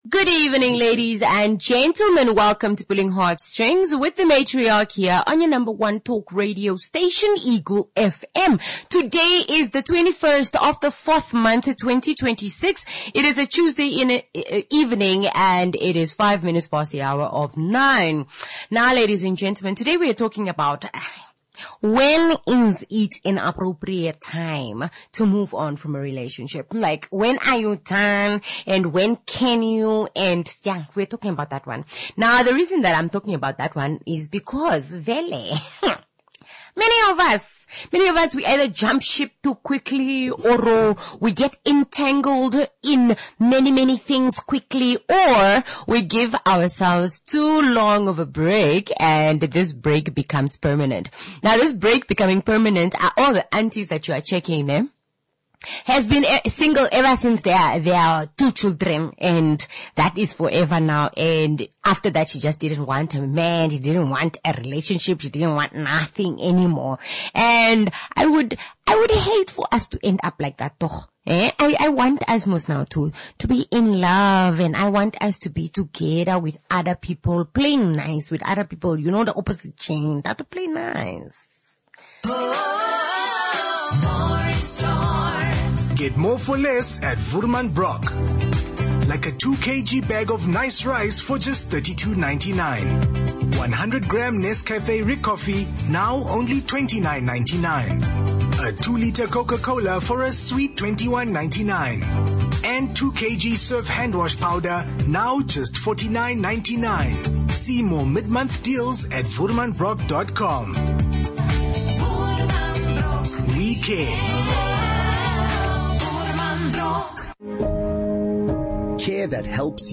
A holistic view and an indepth conversation bound to spark a thought or 2 and definitely an answer of an unwanted pattern